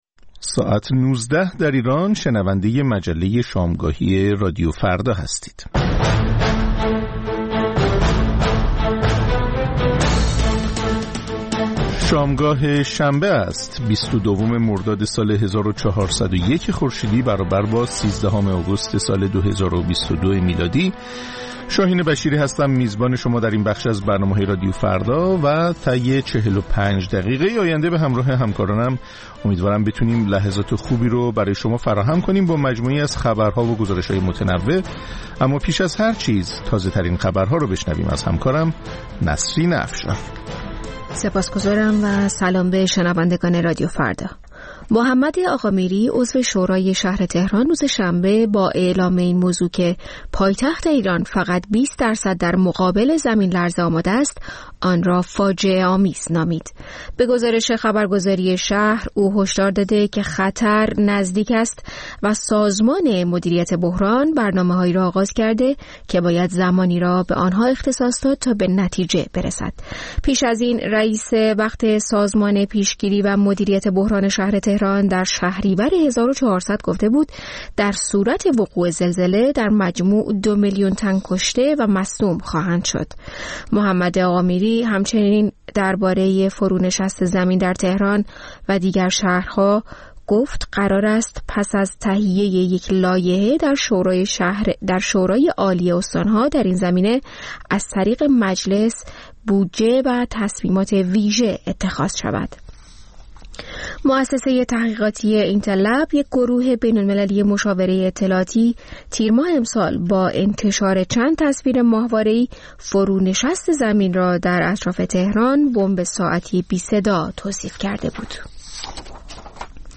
مجموعه‌ای متنوع از آنچه در طول روز در سراسر جهان اتفاق افتاده است. در نیم ساعت اول مجله شامگاهی رادیو فردا، آخرین خبرها و تازه‌ترین گزارش‌های تهیه‌کنندگان رادیو فردا پخش خواهد شد. در نیم ساعت دوم شنونده یکی از مجله‌های هفتگی رادیو فردا خواهید بود.